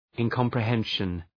Shkrimi fonetik{ın,kɒmprı’henʃən}